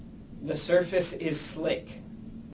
HAS-Corpus / Audio_Dataset /neutral_emotion /163_NEU.wav